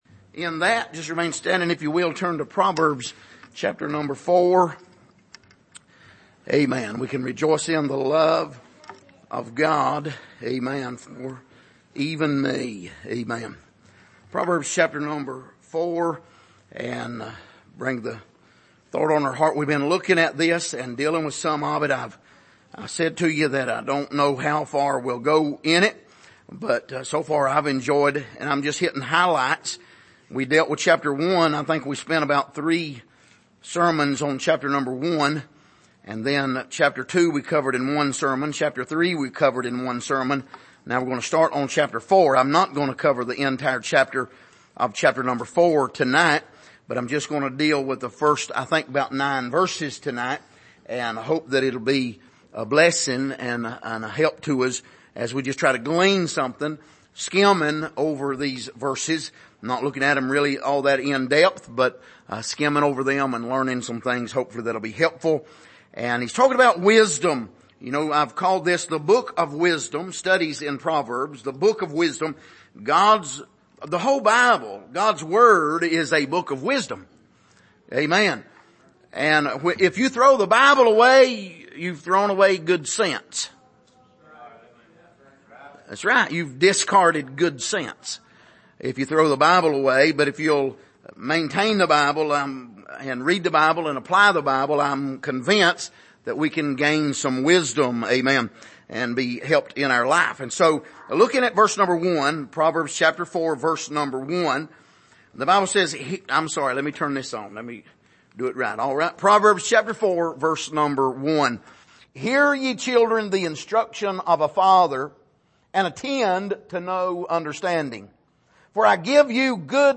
Passage: Proverbs 4:1-9 Service: Sunday Evening